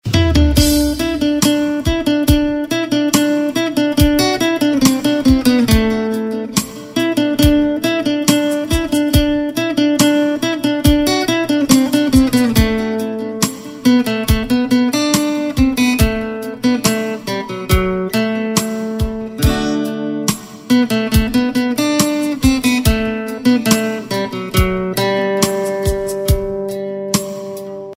Indian POP Ringtones